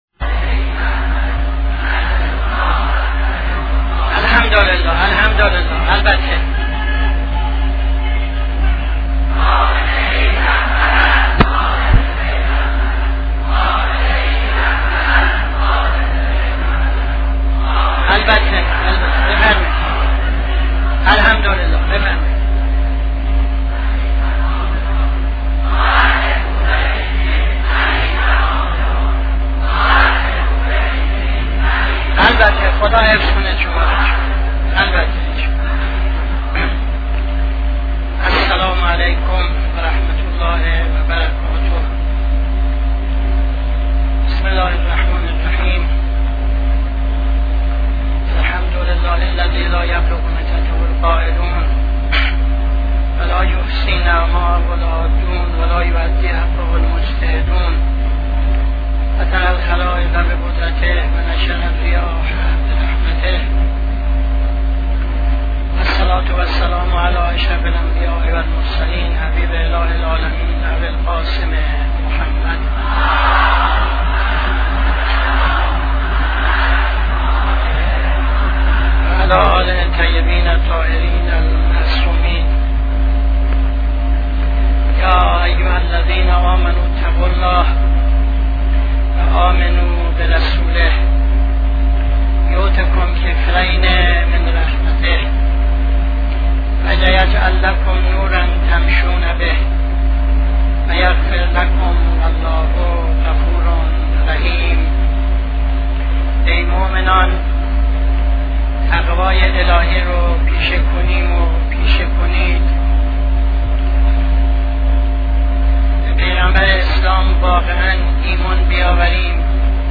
خطبه اول نماز جمعه 07-09-76